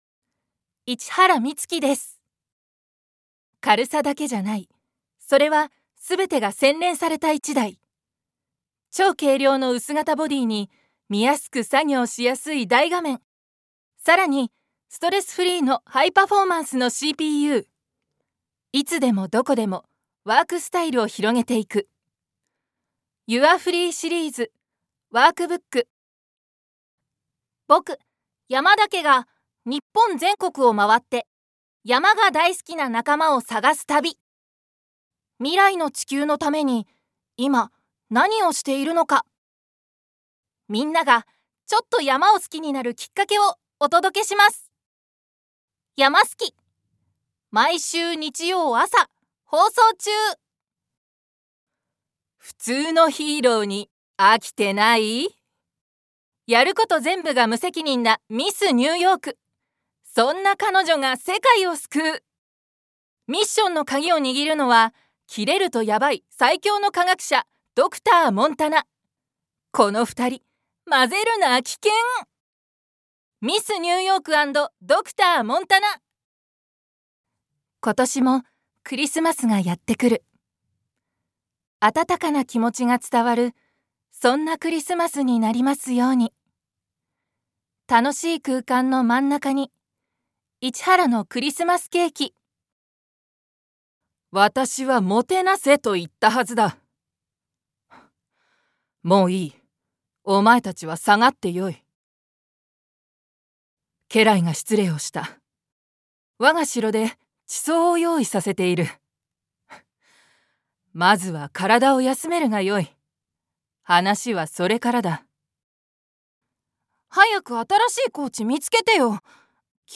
所属：サロン・ド・アクトゥリス出身：千葉県生年月日：６月22日星座：双子座血液型：AB型身長：175cm One shot Voice（サンプルボイスの視聴）